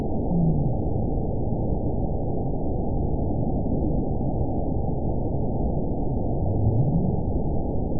event 917861 date 04/19/23 time 02:56:59 GMT (2 years ago) score 9.44 location TSS-AB06 detected by nrw target species NRW annotations +NRW Spectrogram: Frequency (kHz) vs. Time (s) audio not available .wav